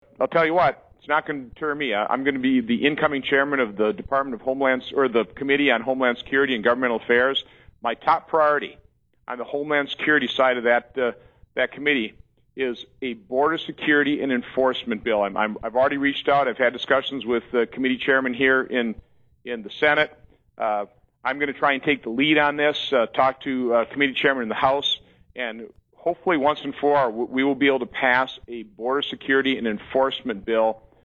Senator Johnson gave these answers during an interview on Thursday, Nov. 20, with WSAU News/Talk AM 550.